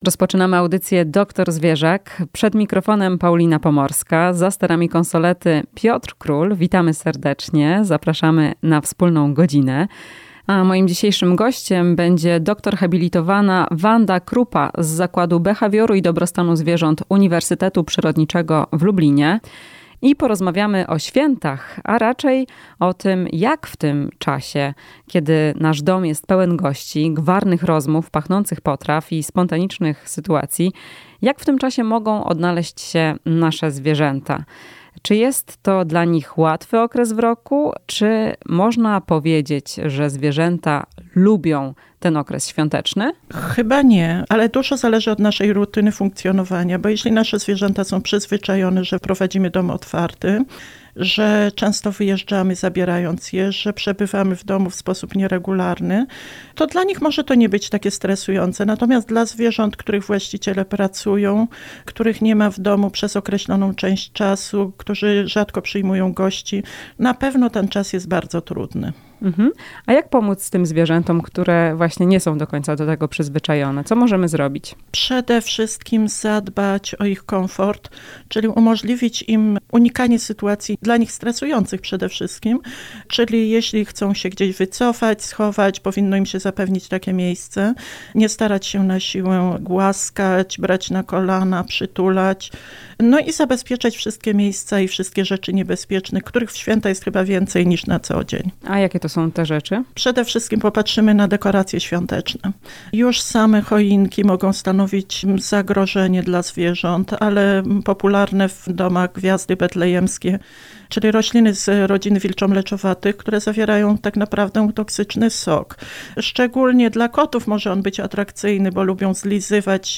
To wszystko w rozmowie z dr hab.